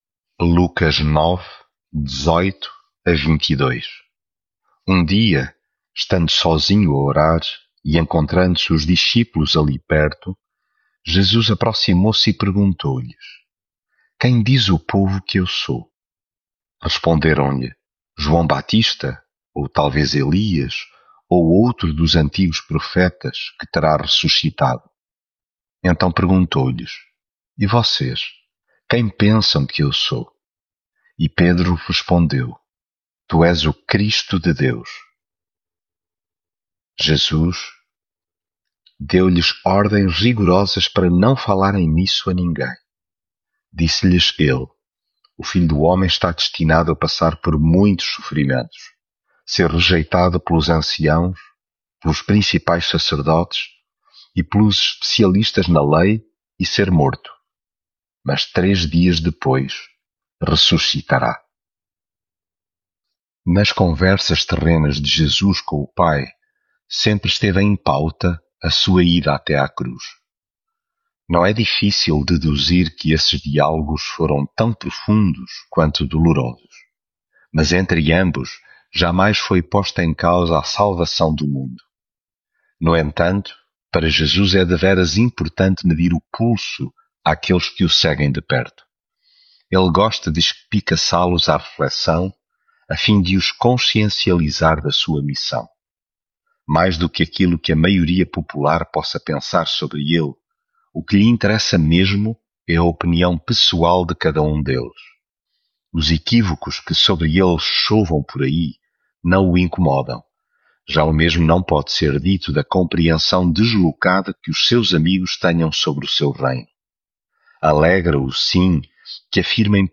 devocional Lucas leitura bíblica Um dia, estando sozinho a orar e encontrando-se os discípulos ali perto, Jesus aproximou-se e perguntou-lhes: “Quem diz o povo que...